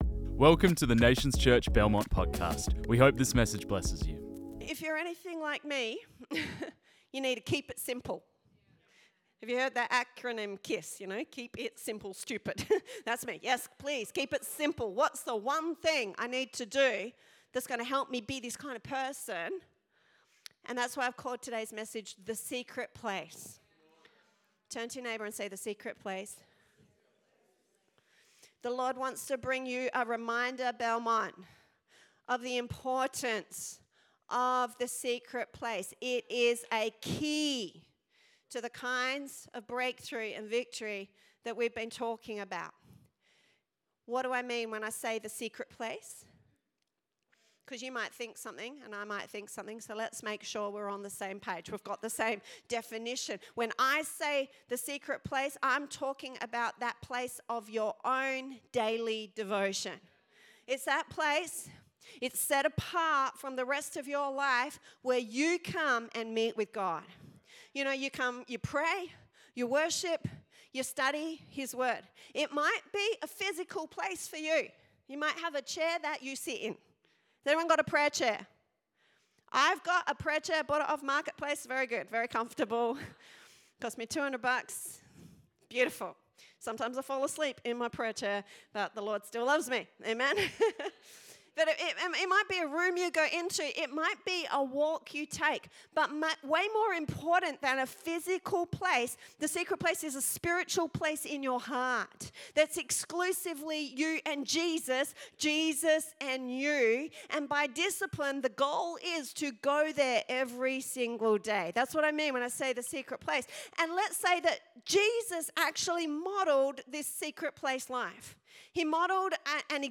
This message was preached on 20 July 2025.